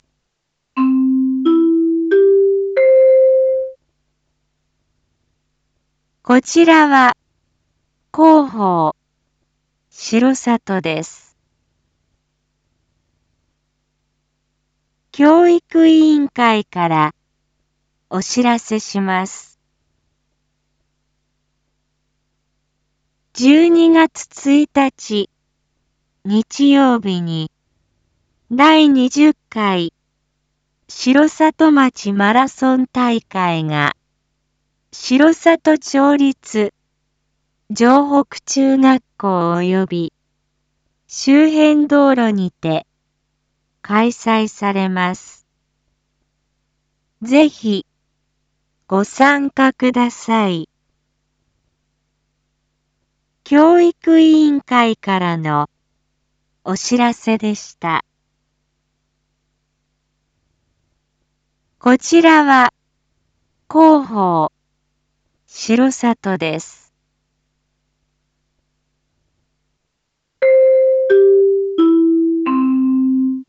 Back Home 一般放送情報 音声放送 再生 一般放送情報 登録日時：2024-11-12 19:01:14 タイトル：第20回城里町マラソン大会開催について⑤ インフォメーション：こちらは広報しろさとです。